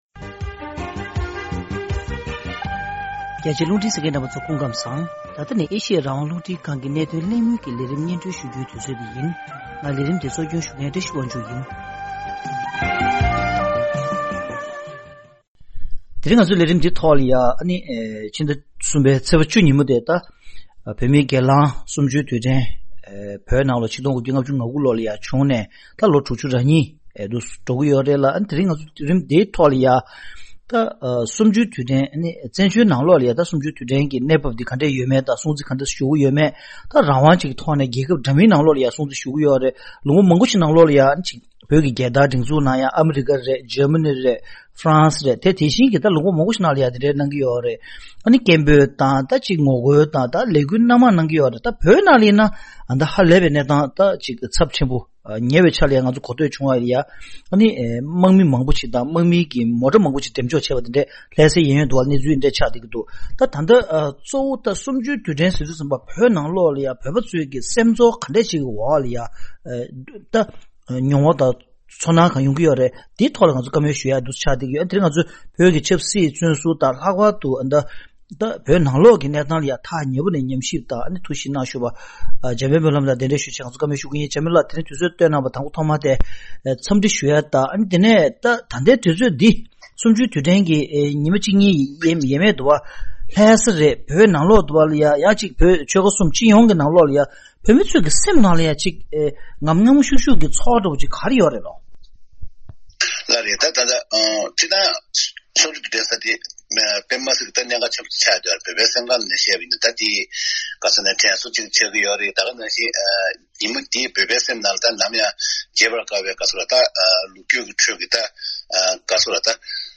གནད་དོན་གླེང་མོལ་གྱི་ལས་རིམ